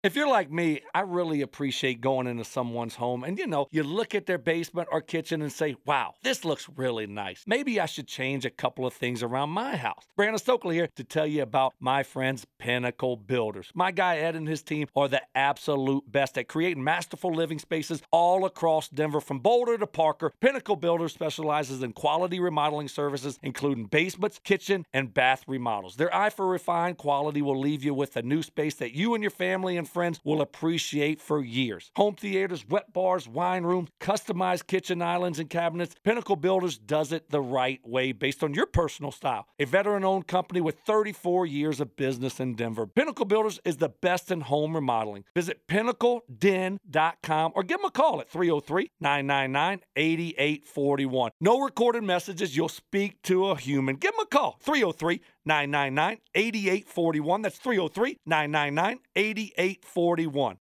Listen to Our Endorsement from Brandon Stokley